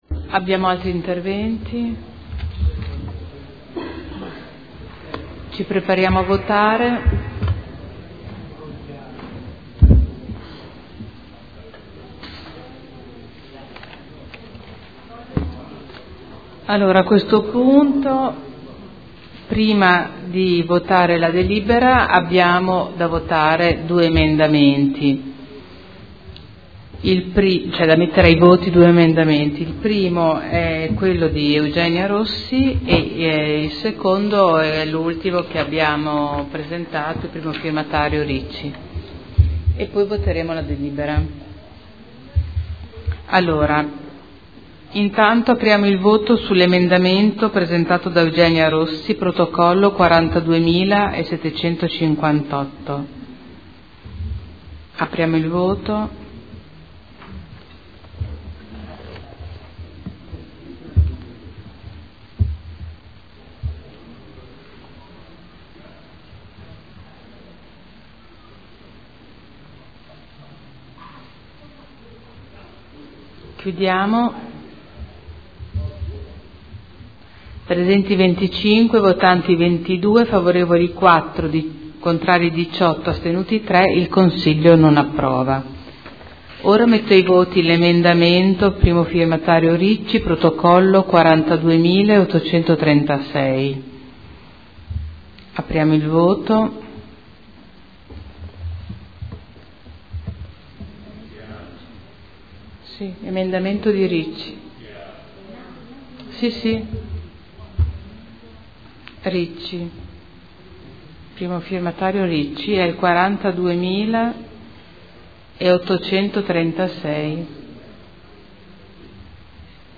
Seduta del 7 aprile.